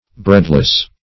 Search Result for " breadless" : The Collaborative International Dictionary of English v.0.48: Breadless \Bread"less\, a. Without bread; destitute of food.